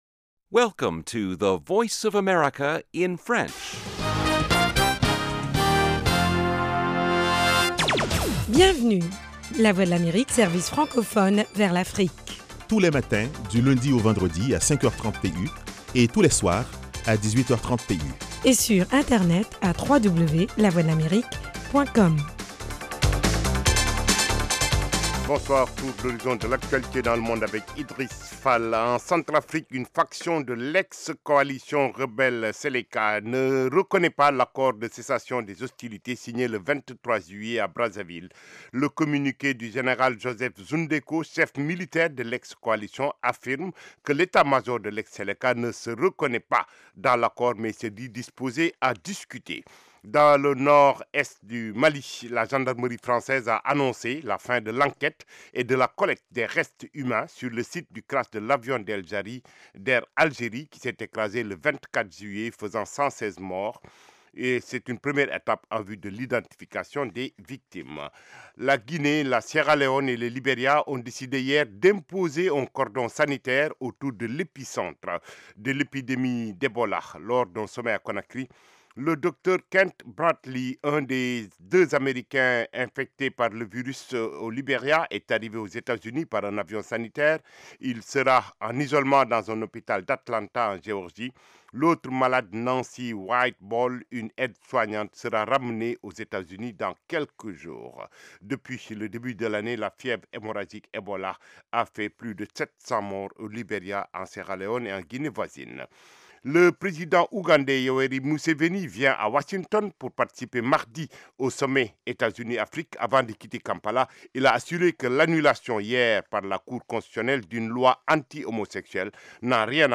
Le magazine vous tient aussi au courant des dernières découvertes en matière de technologie et de recherche médicale. Carrefour Santé et Sciences vous propose aussi des reportages sur le terrain concernant les maladies endémiques du continent : paludisme, sida, polio, grippe aviaire…